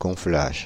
Ääntäminen
Ääntäminen Paris: IPA: [gɔ̃.flaʒ] France (Île-de-France): IPA: /gɔ̃.flaʒ/ Haettu sana löytyi näillä lähdekielillä: ranska Käännös Konteksti Substantiivit 1. carga {f} urheilu Suku: m .